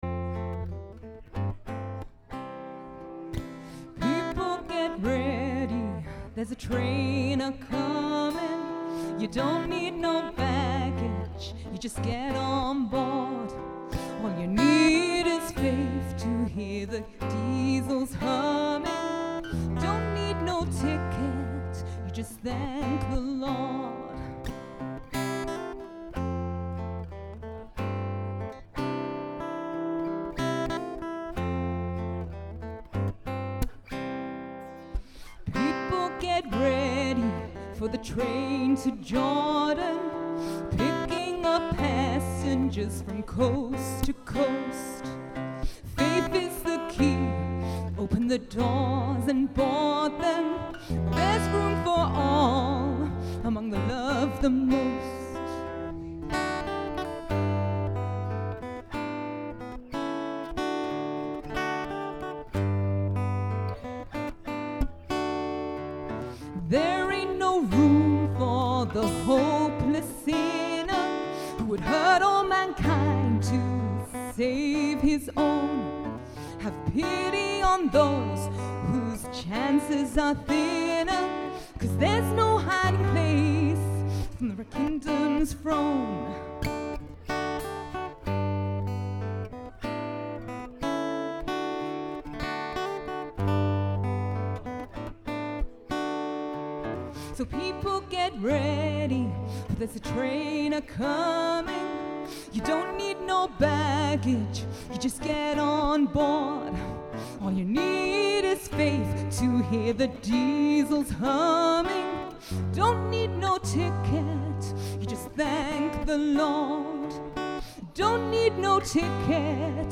Live recording with Guitarist